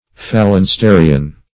phalansterian - definition of phalansterian - synonyms, pronunciation, spelling from Free Dictionary
Search Result for " phalansterian" : The Collaborative International Dictionary of English v.0.48: Phalansterian \Phal`an*ste"ri*an\, a. [F. phalanst['e]rien, a. & n.]
phalansterian.mp3